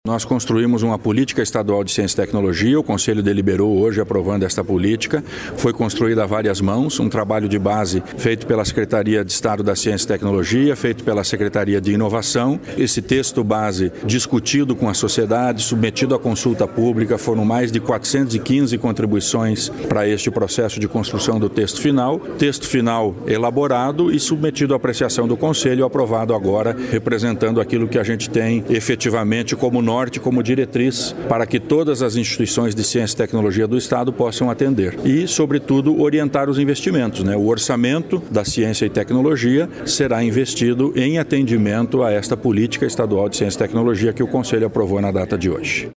Sonora do secretário de Ciência, Tecnologia e Ensino Superior, Aldo Bona, sobre a nova Política de Ciência e Tecnologia com validade até 2030